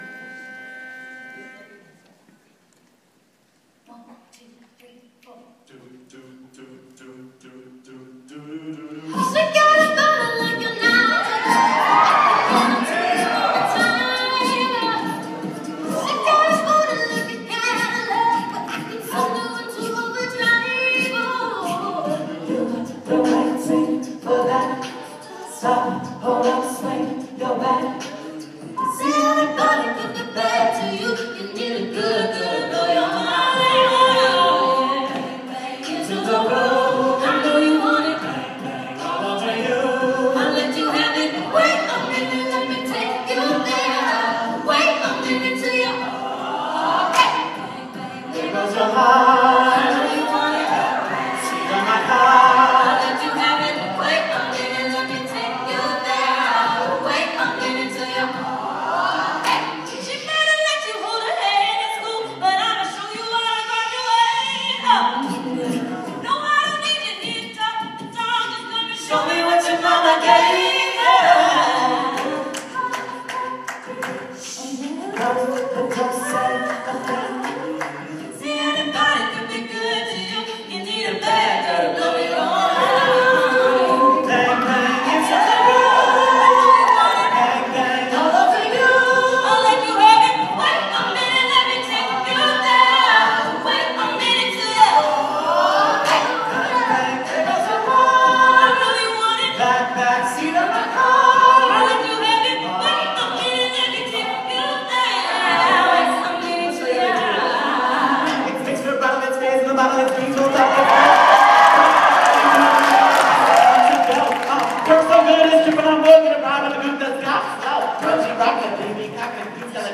Good fun with one of UM’s a capella group.